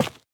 sounds / block / tuff / break3.ogg